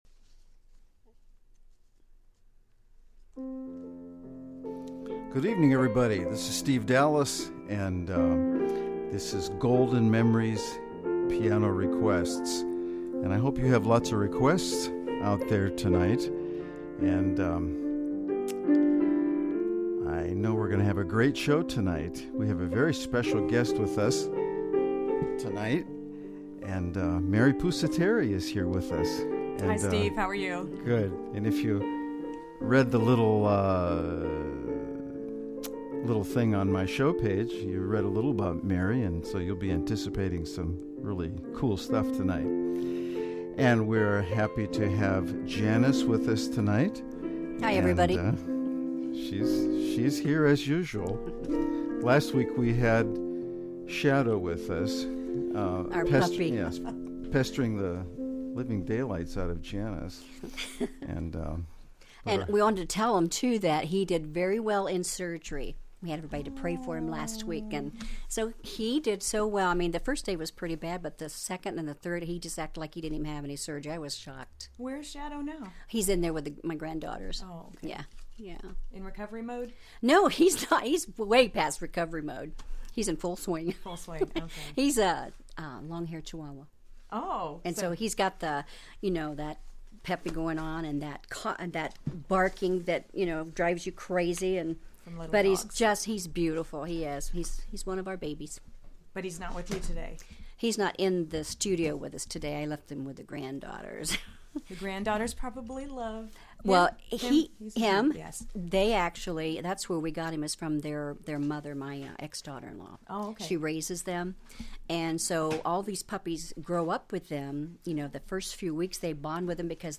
Talk Show Episode
Golden oldies played to perfection!
There will be lots of stories and singing; you can count on that!